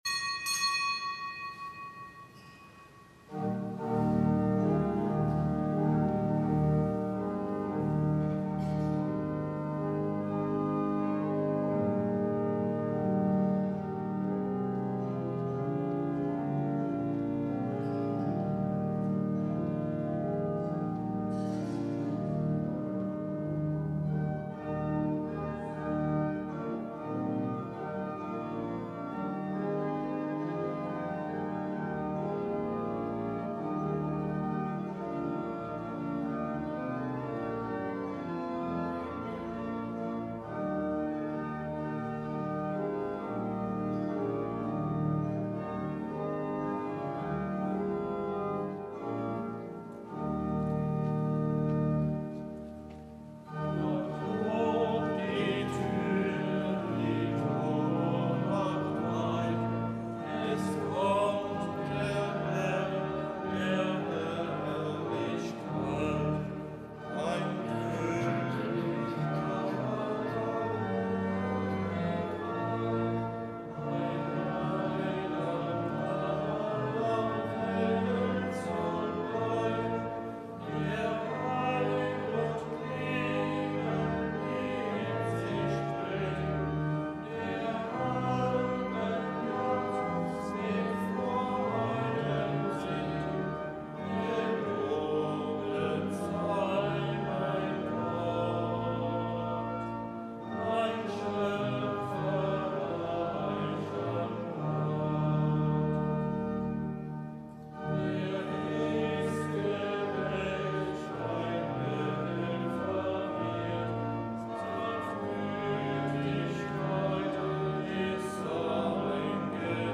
Kapitelsmesse aus dem Kölner Dom am Montag der ersten Adventswoche. Zelebrant_ Dompropst Guido Assmann.